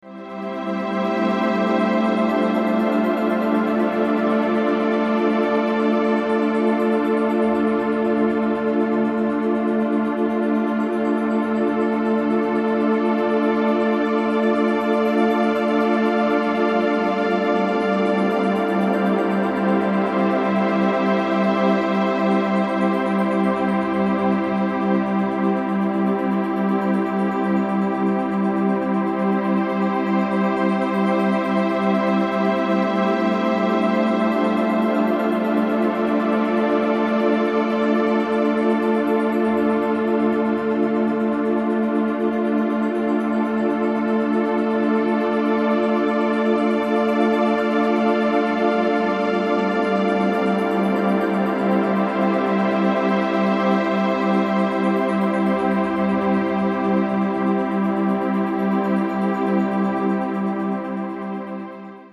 左右の耳から違う周波数の音を聞くことで、脳が各々に流れる周波数の差異を感知。
ココロはずませる音色が凹んだ気持ちをほがらかに